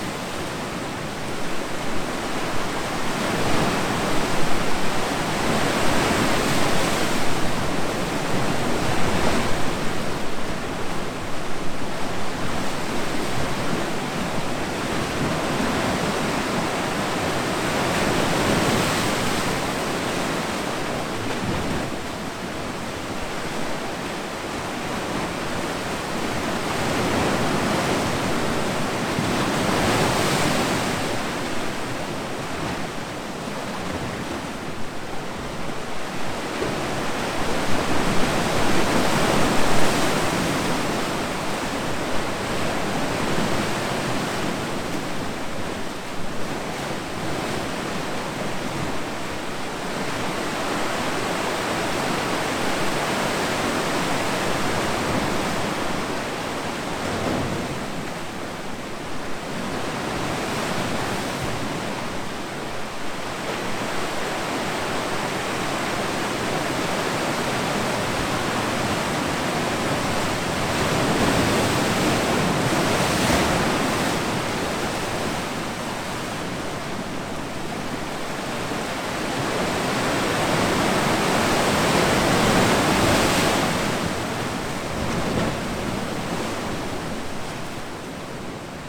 ocean-3.ogg